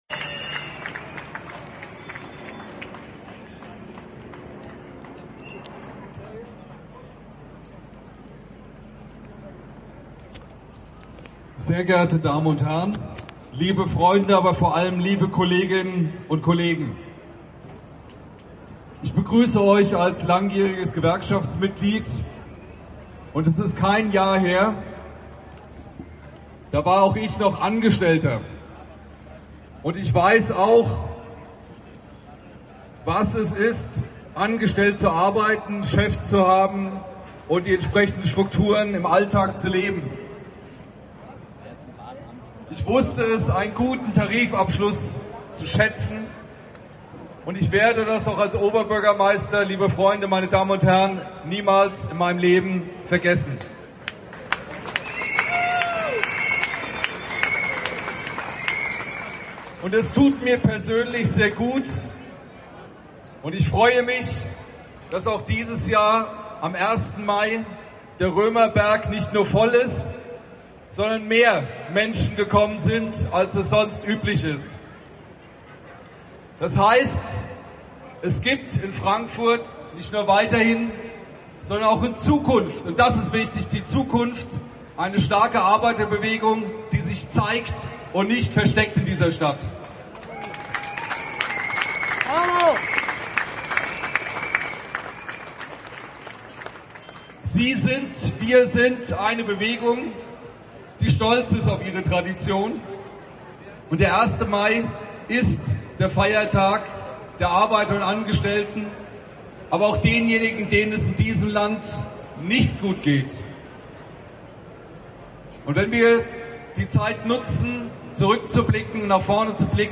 Der Frankfurter Oberbürgermeister Feldmann.
Kundgebung 1.5.13 Römerberg OB Feldmann